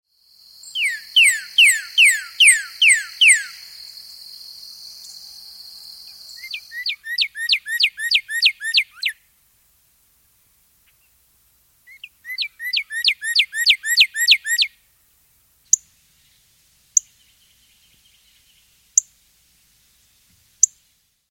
Birdcalls
Cardinal
cardinal.mp3